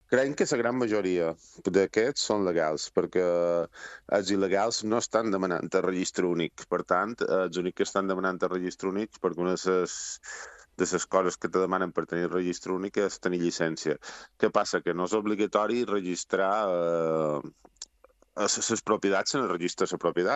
En una entrevista a l’Informatiu Matí d’IB3 Ràdio